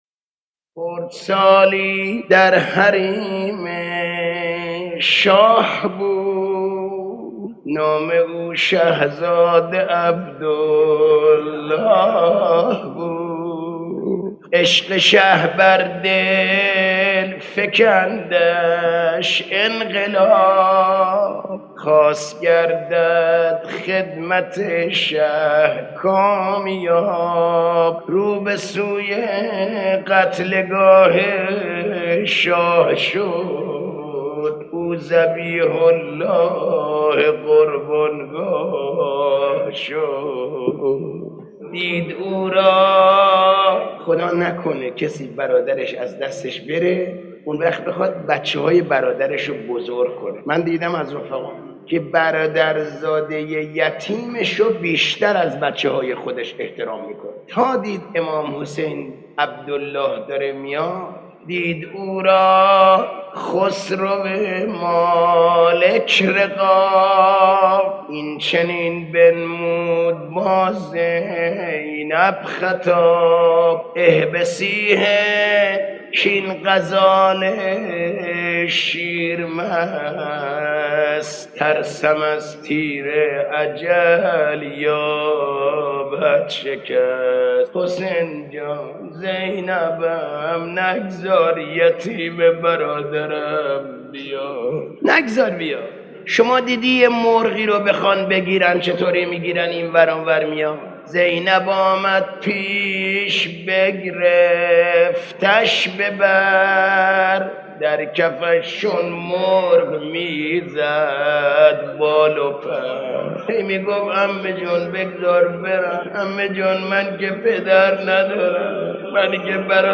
در پرده عشاق، صدای مداحان و مرثیه‌خوانان گذشته تهران قدیم را خواهید شنید که صدا و نفسشان شایسته ارتباط دادن مُحب و مَحبوب بوده است.
مرثیه و مصیبت‌خوانی درباره حضرت عبدالله بن حسن (ع)